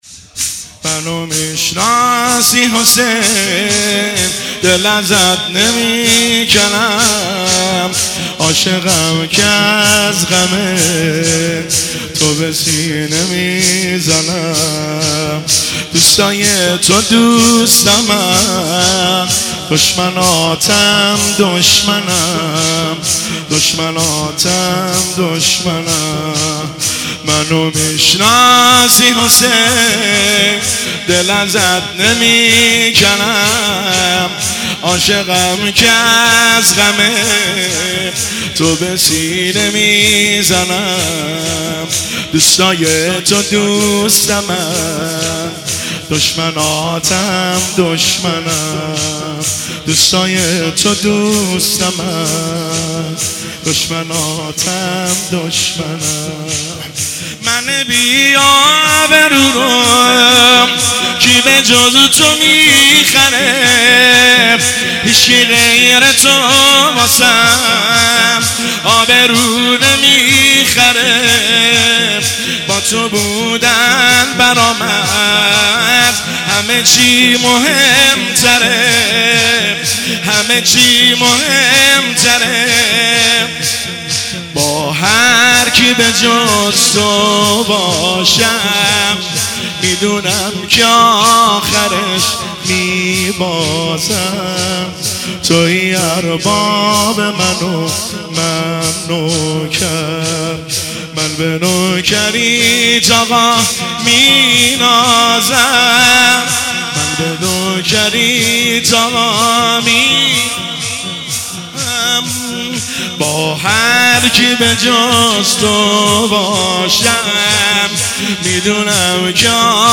منو میشناسی حسین دل ازت نمیکَنم شور – شب دوم ایام مسلمیه 1404 هیئت بین الحرمین طهران